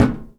metal_tin_impacts_deep_06.wav